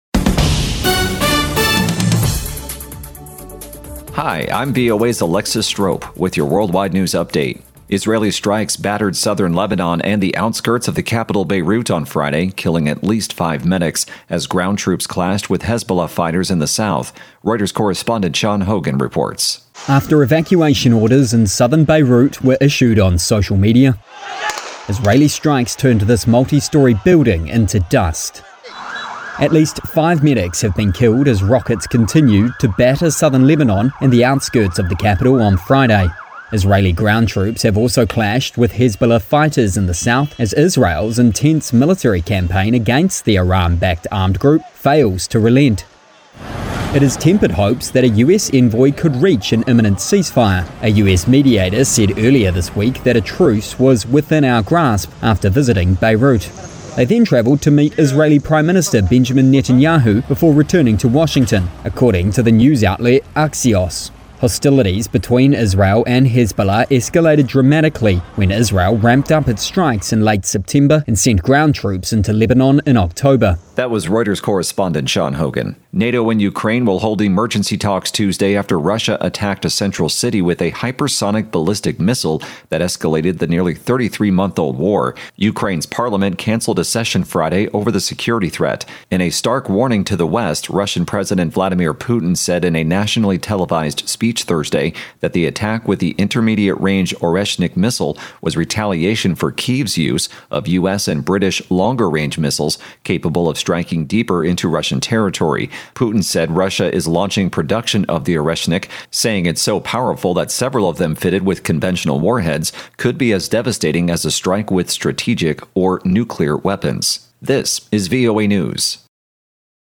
VOA 2-min news の音声はかなり速いので、プレイヤーの ボタンを押して、ゆっくりにしてシャドウイングを試してみても効果があります。